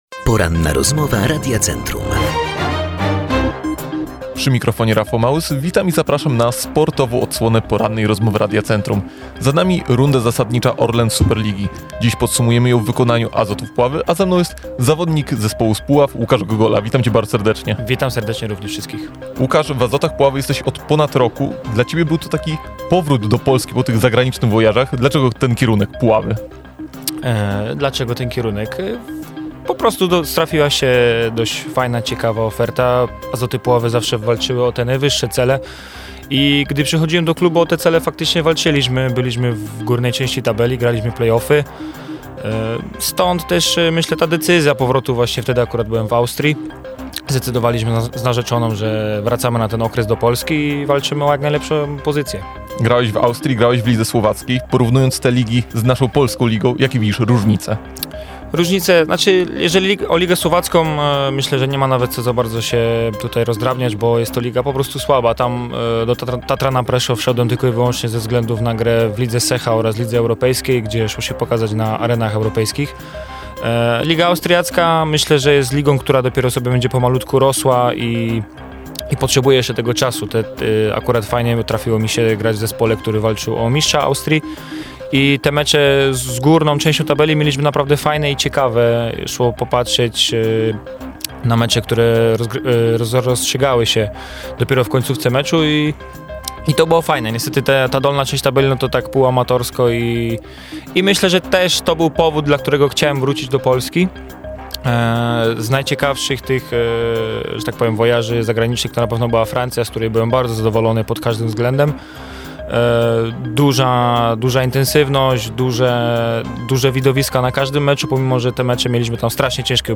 ROZMOWA.mp3